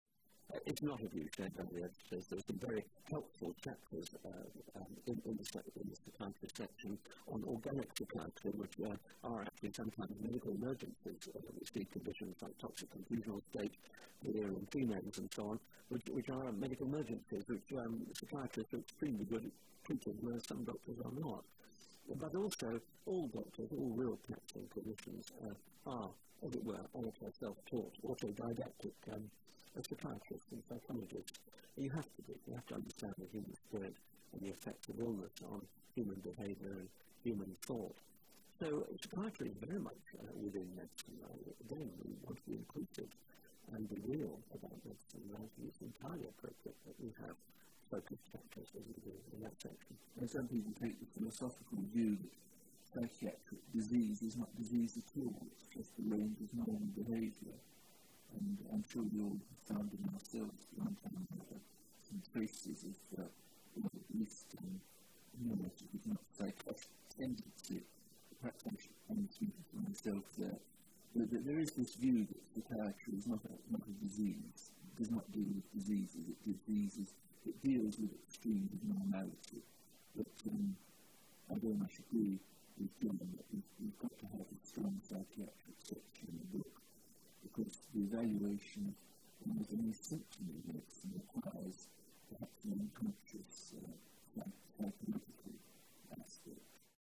So I hope that this interview will be of interest to medics and non-medics alike.